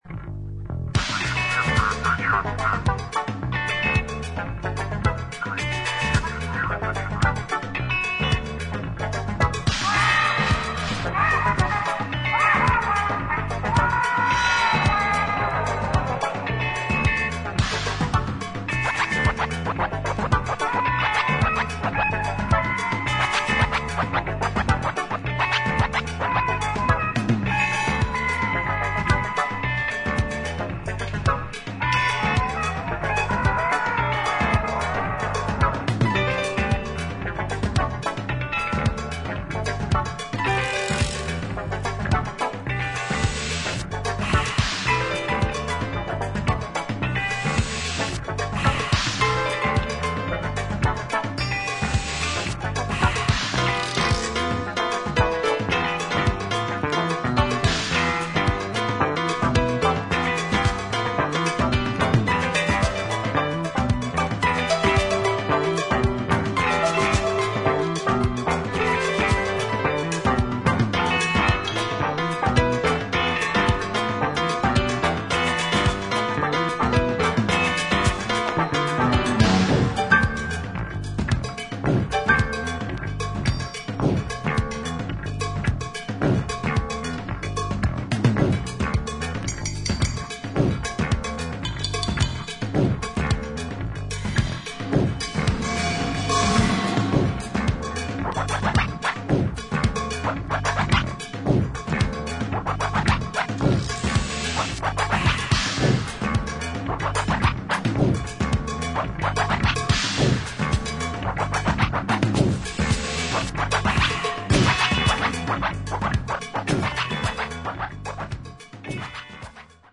80年代のエレクトロニック・サウンドにワールドミュージックの要素を絶妙に取り入れたアヴァンギャルド作品